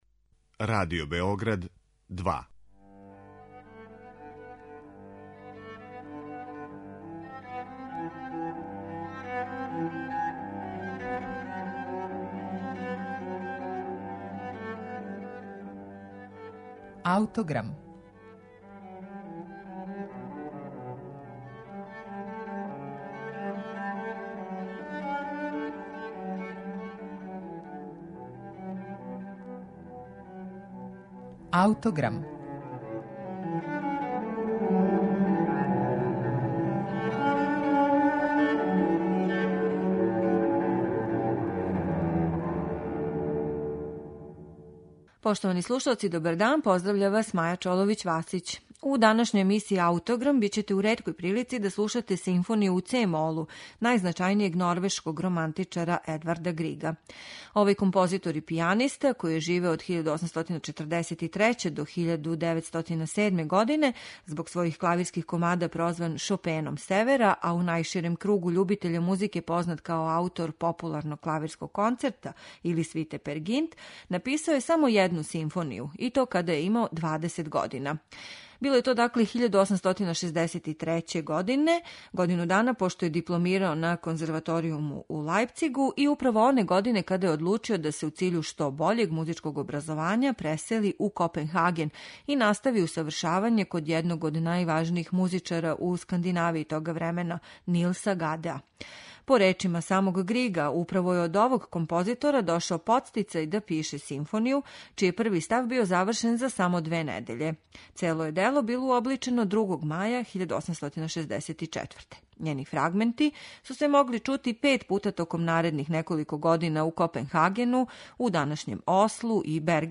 Стилски обликована на трагу музике Бетовена, Менделсона и Шумана, ова младалачка композиција одражава један (у историји развоја симфонијске музике) статични период, конзервативне музичке ставове тог времена, али најављује и неколико зрелих карактеристика будућег композитора - пре свега изразити лиризам.